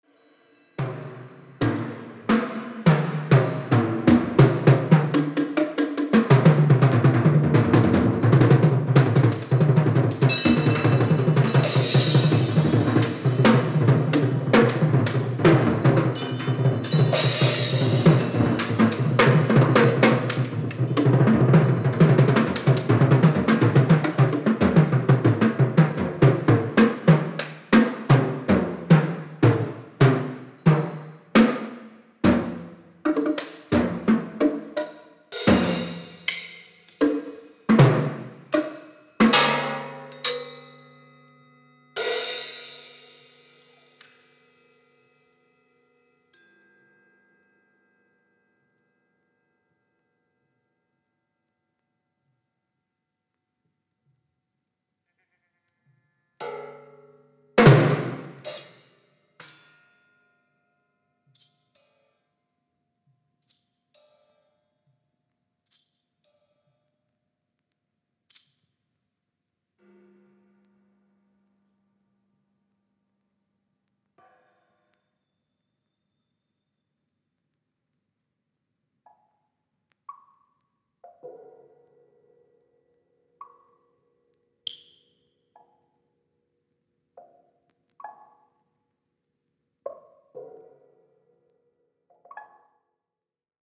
研ぎ澄まされた空気感に暖かさすら感じます！！！